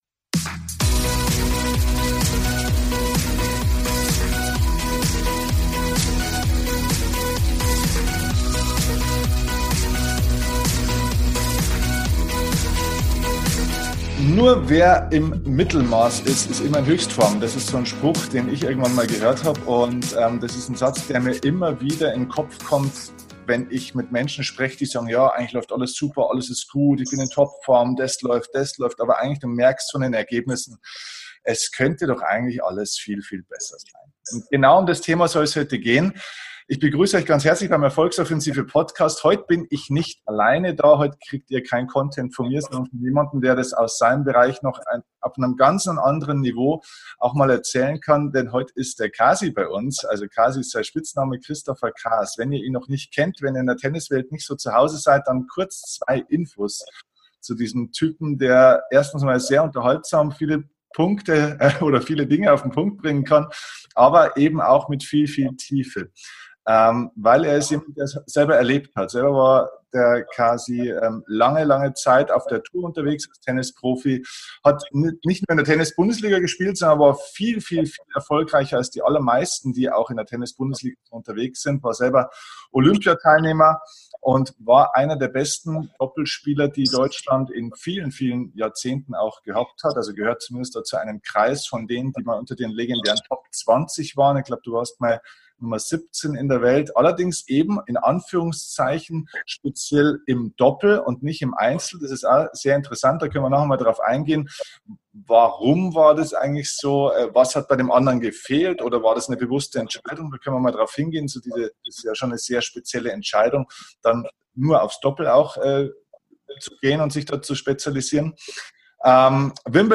Heute ist ein ehemaliger Weltklasse-Spieler bei mir zu Gast im Podcast.